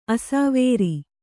♪ asāvēri